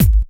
keys_23.wav